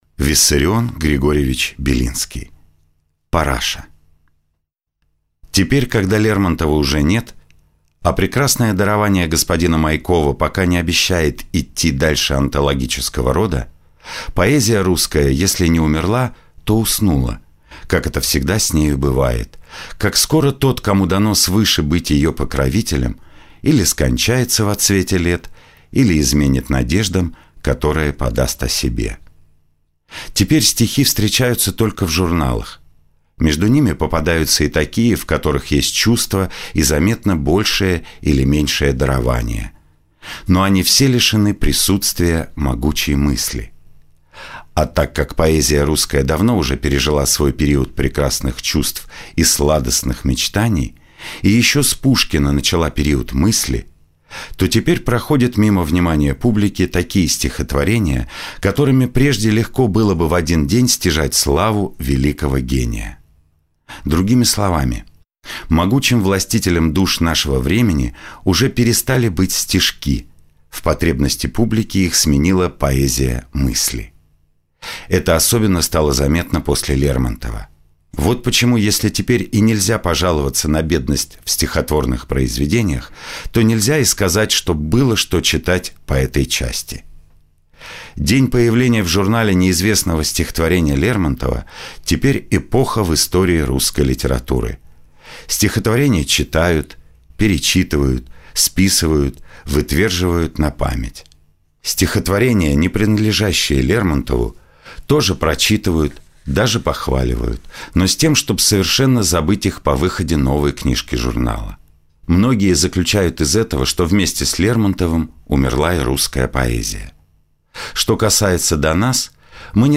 Аудиокнига Параша | Библиотека аудиокниг